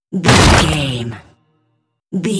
Dramatic hit wood bloody
Sound Effects
heavy
intense
dark
aggressive
hits